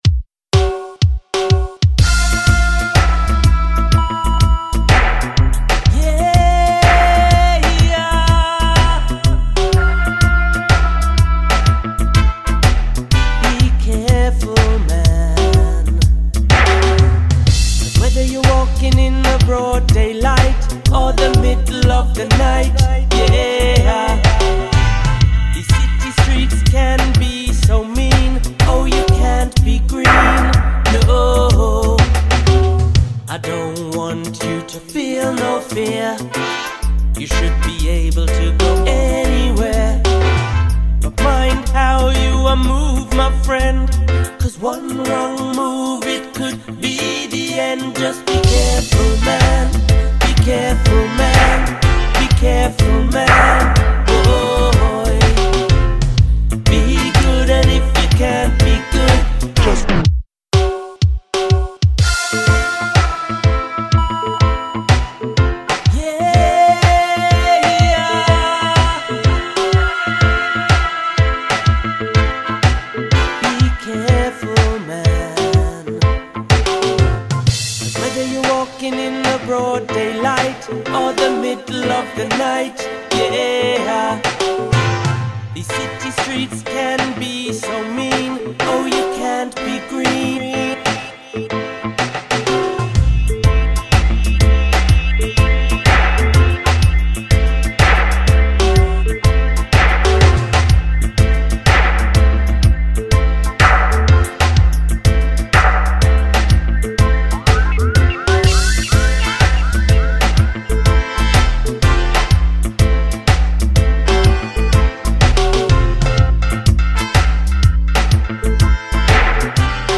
HORNS
BACKING VOCALS
FLUTE
HARP
BONGOS
ROOTS AND CULTURE